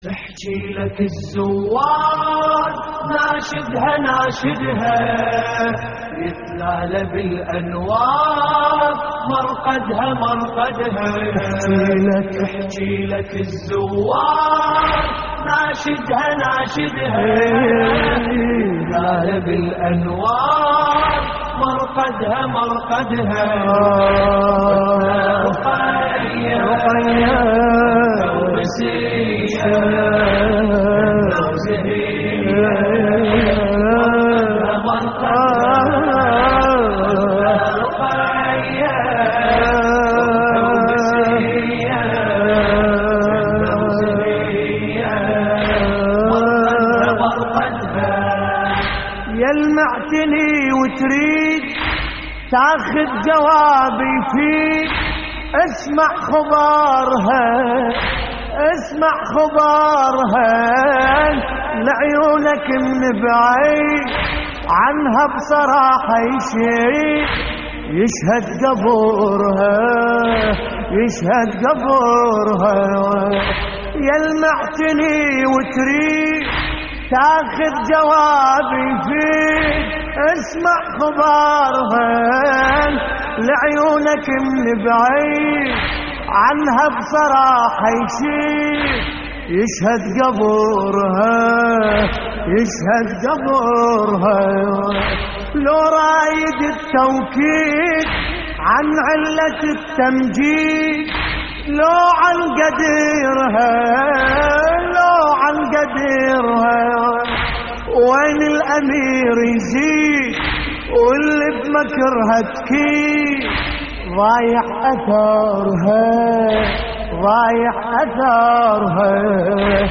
تحميل : تحكيلك الزوار ناشدها ناشدها - السيدة رقية / الرادود باسم الكربلائي / اللطميات الحسينية / موقع يا حسين
موقع يا حسين : اللطميات الحسينية تحكيلك الزوار ناشدها ناشدها - السيدة رقية - استديو لحفظ الملف في مجلد خاص اضغط بالزر الأيمن هنا ثم اختر (حفظ الهدف باسم - Save Target As) واختر المكان المناسب